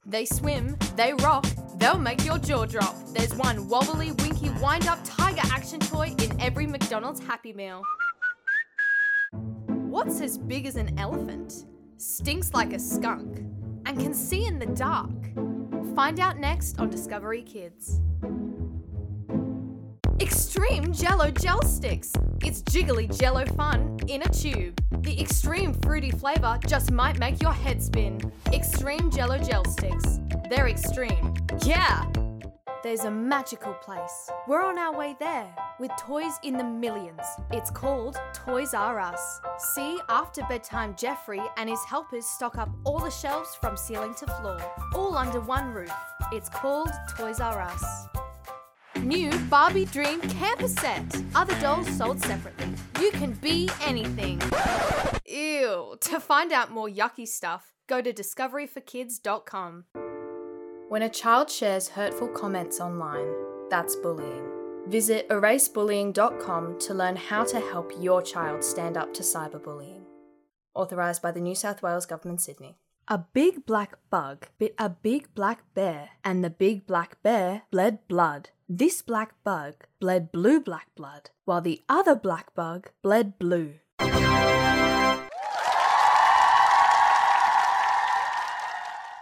Teenager (13-17)